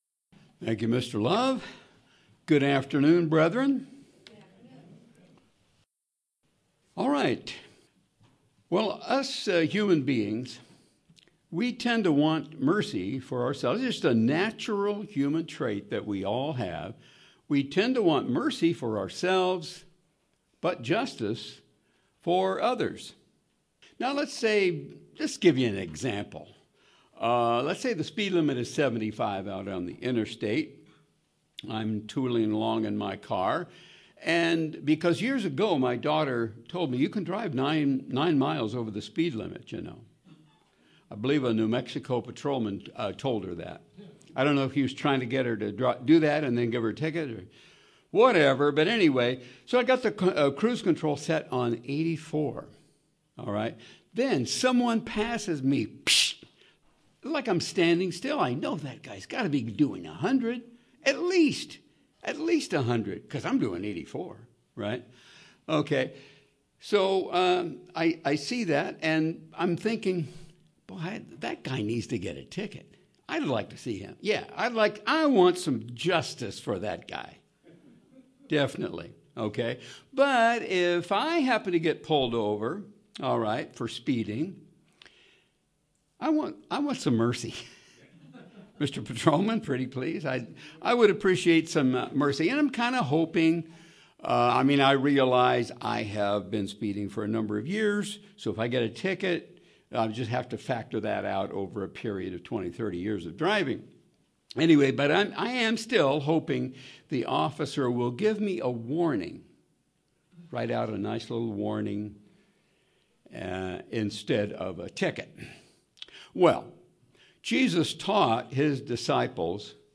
Sermons
Given in Yuma, AZ